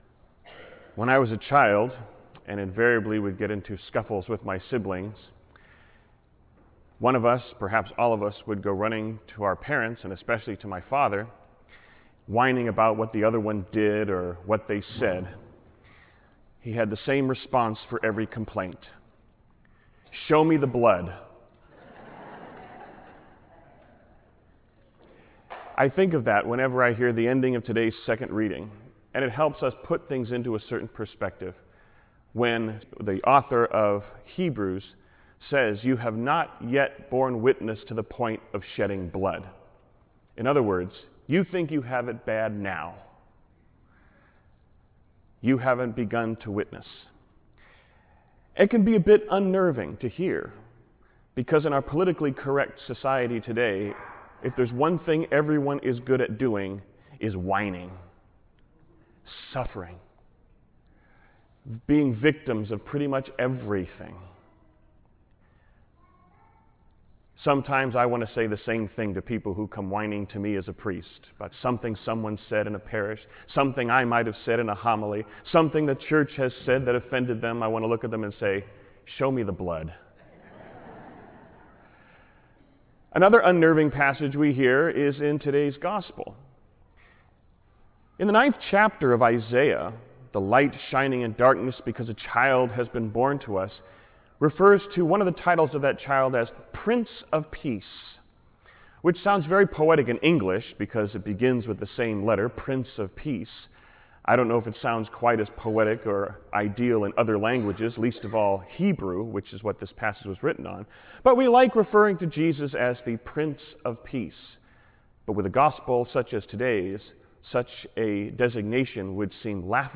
Homily for the 19th Sunday of the Year (Luke 12:32-34)
Homily-20thSundayCwebsite.wav